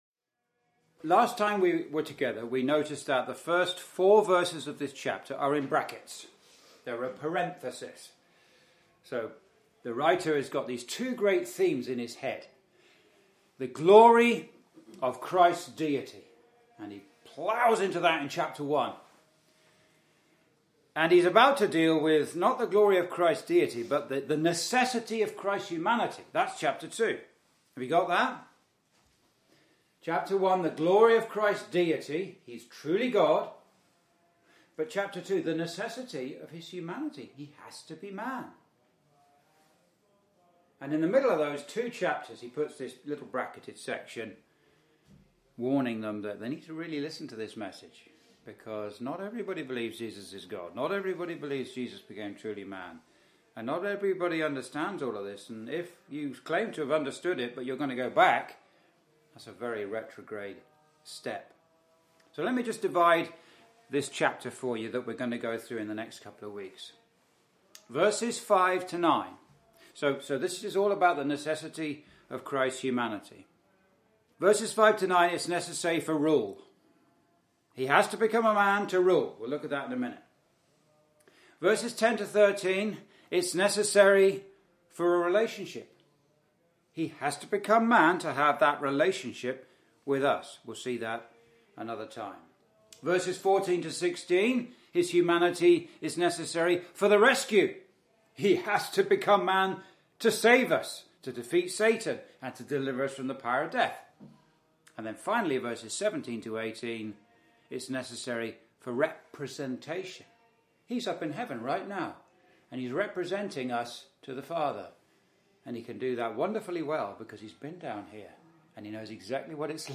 Verse by Verse Exposition
(Message preached in Chalfont St Peter Gospel Hall, 2024)